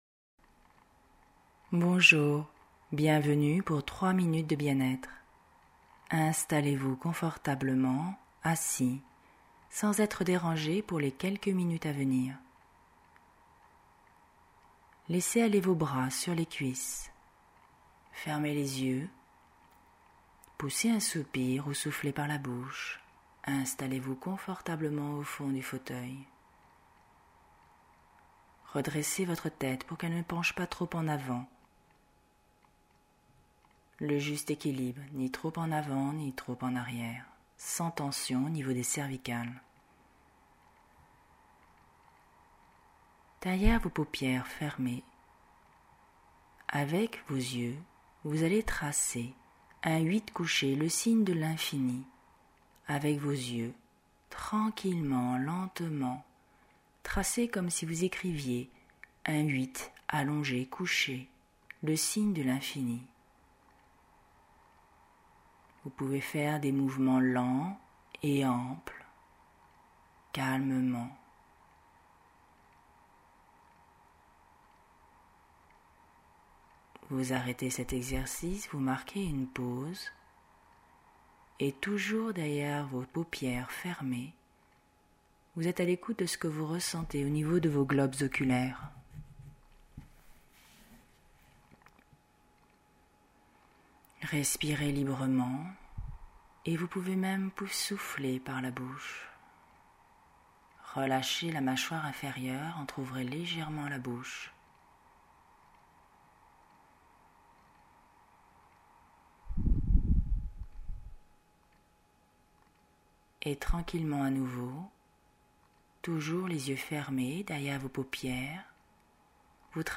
Genre : sophro
relaxation-yeux.mp3